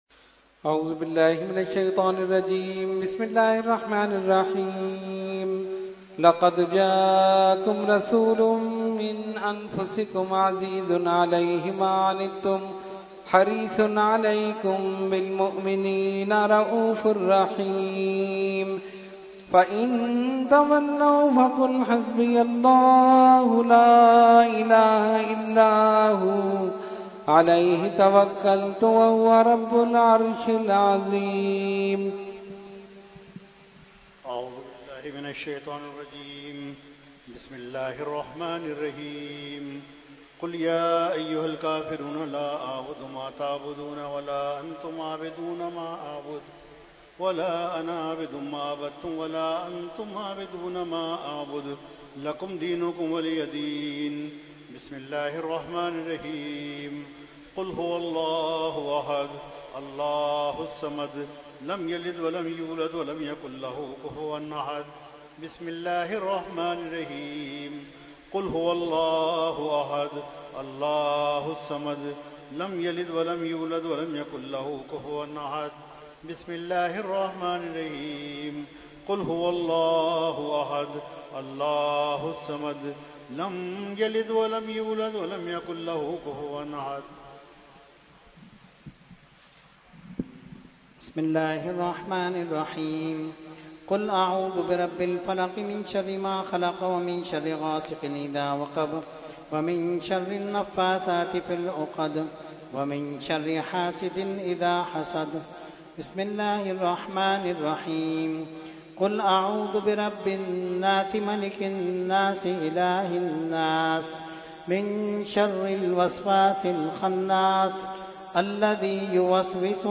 Fatiha Dua – Khatmul Quran 2018 – Dargah Alia Ashrafia Karachi Pakistan